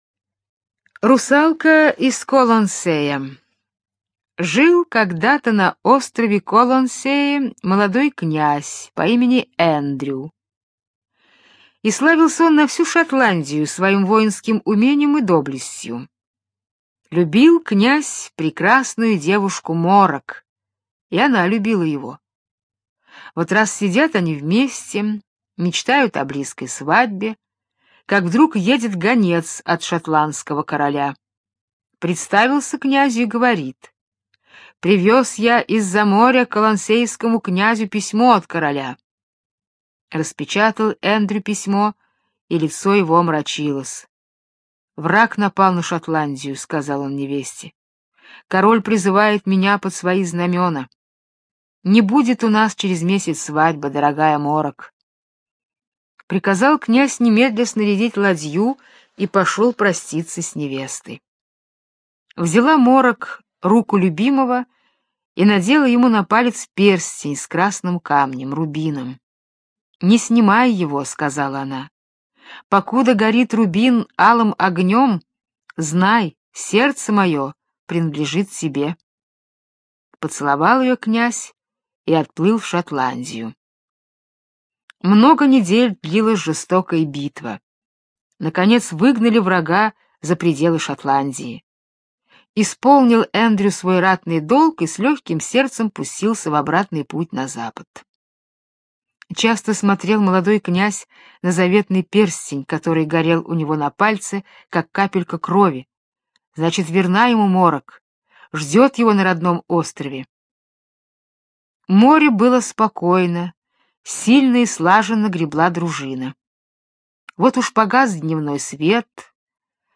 Русалка из Колонсея - британская аудиосказка - слушать онлайн